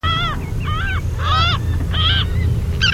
Gavina corsa (Larus audouinii)
Larus.audouinii.mp3